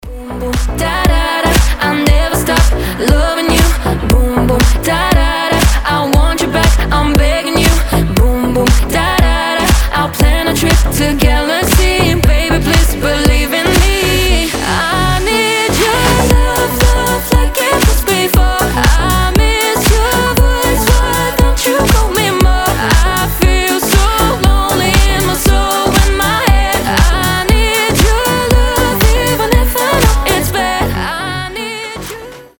• Качество: 320, Stereo
Dance Pop